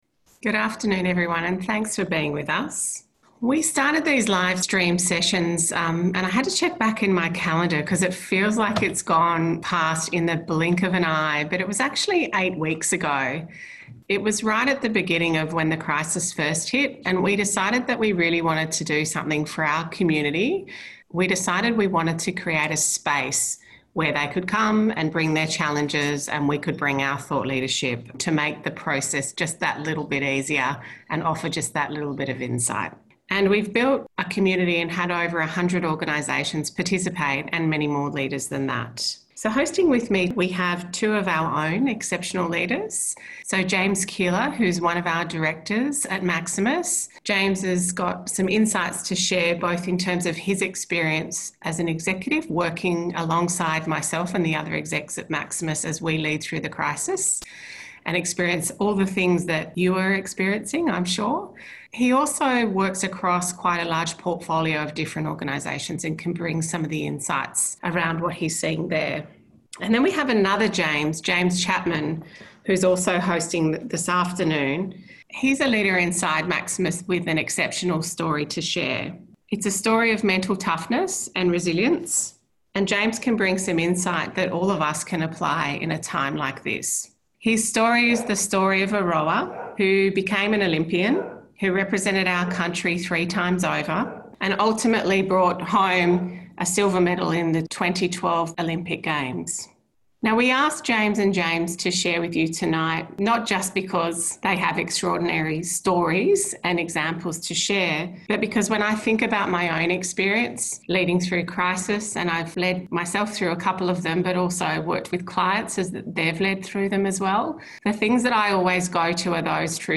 maximus-managing-energy-podcast.mp3